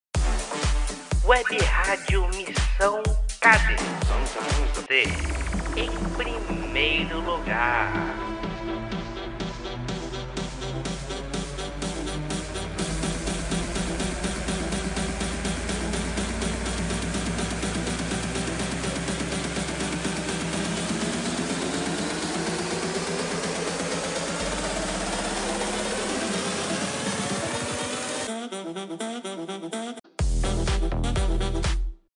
VINHETAS